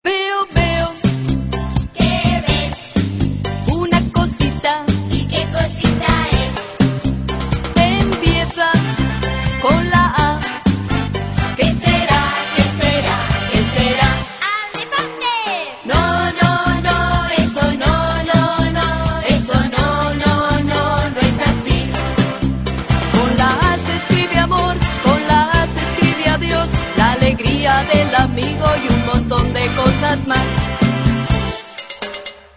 Canciones Infantiles